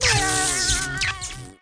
Npc Catzap Sound Effect
npc-catzap-3.mp3